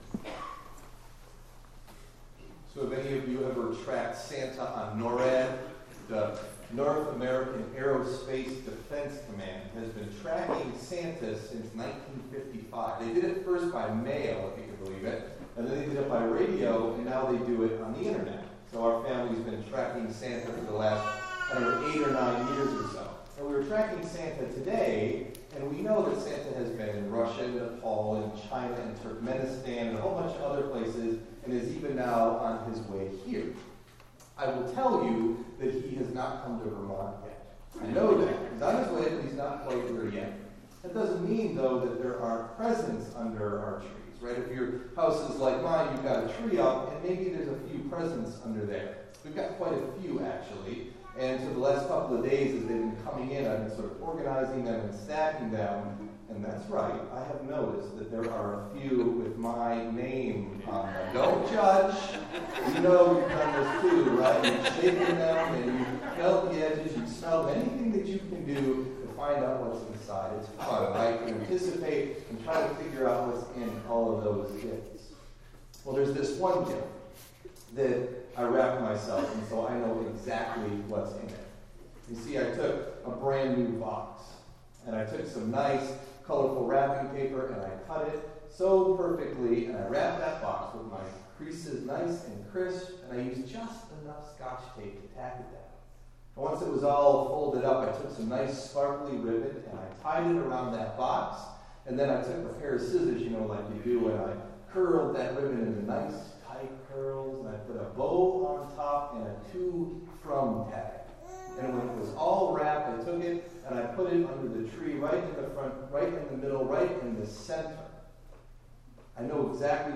Delivered at: The United Church of Underhill (UCC and UMC)